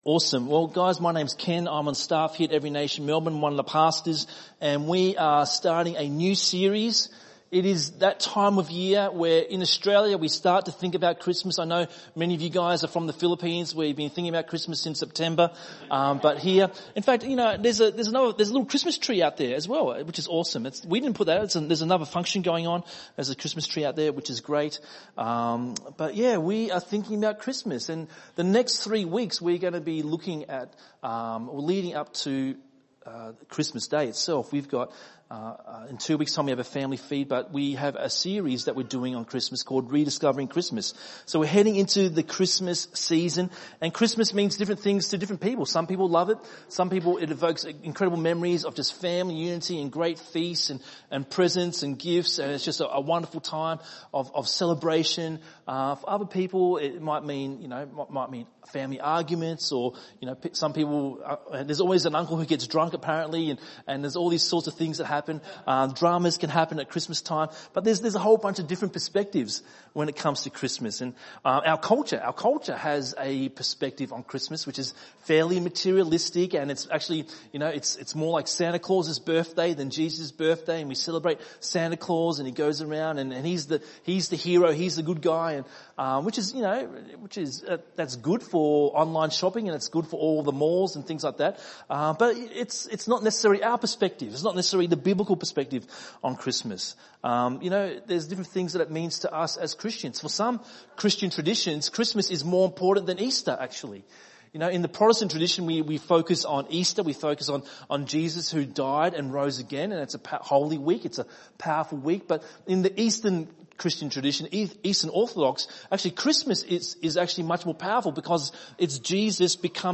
Doing a Bible study or LifeGroup on this sermon – maybe these notes will help?